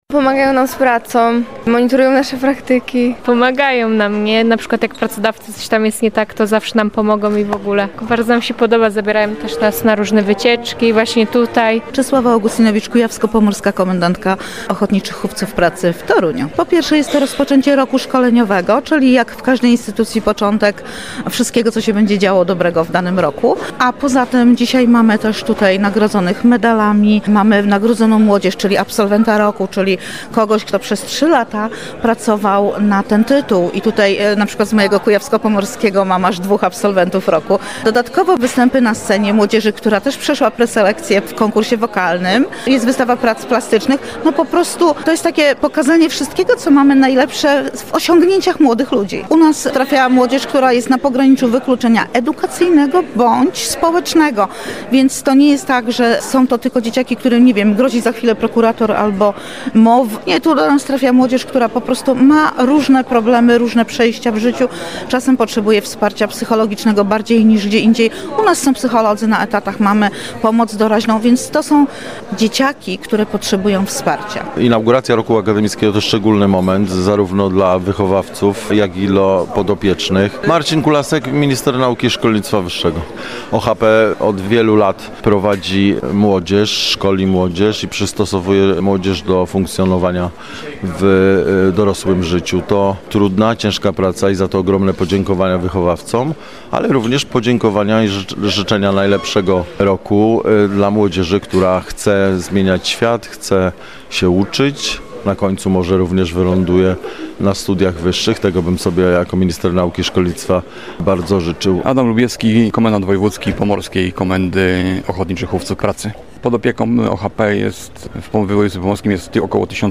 Uhonorowanie najlepszych absolwentów oraz ślubowanie młodzieży pierwszego roku - w Gdańsku odbyła się ogólnopolska inauguracja roku szkoleniowego Ochotniczych Hufców Pracy.
Posłuchaj materiału naszego reportera: https